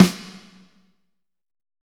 Index of /90_sSampleCDs/Northstar - Drumscapes Roland/SNR_Snares 2/SNR_P_C Snares x